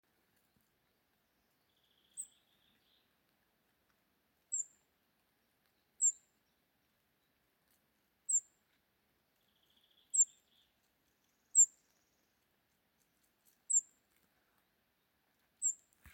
Common Treecreeper, Certhia familiaris
StatusNest containing eggs